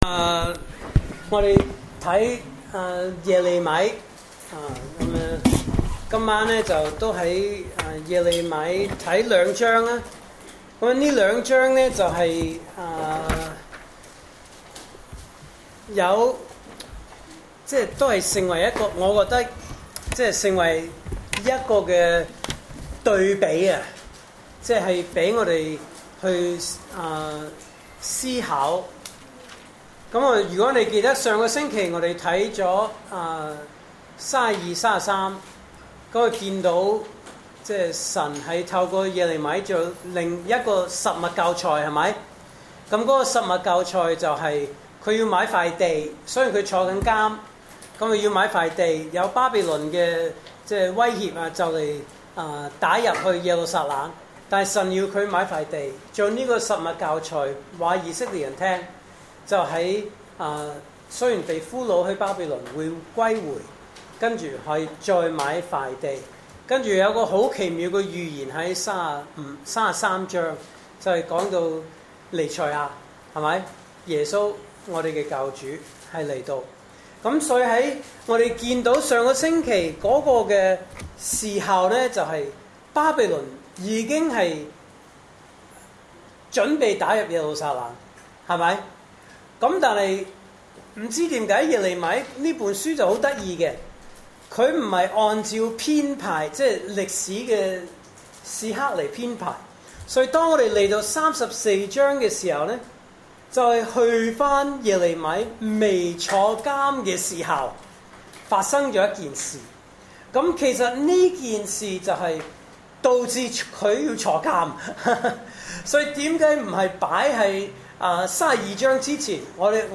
證道信息
來自講道系列 "查經班：耶利米書"